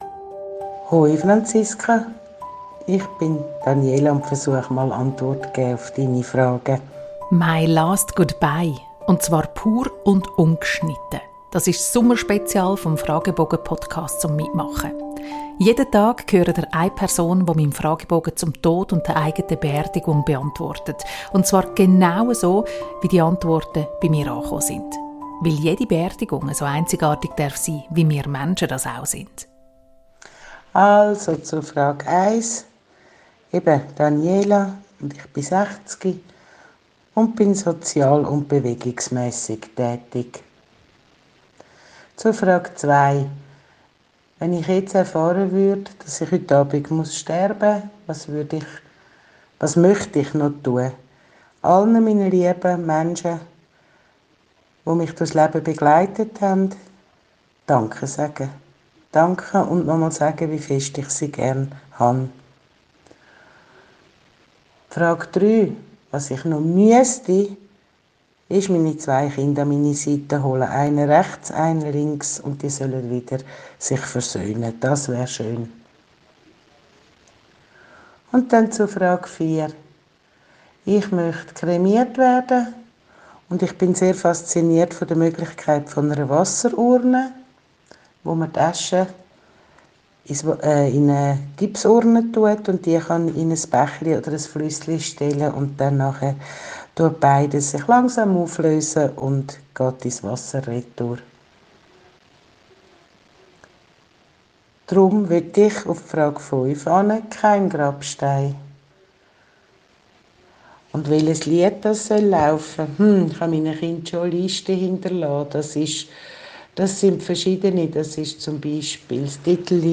MY LAST GOODBYE - pur und ungeschnitten.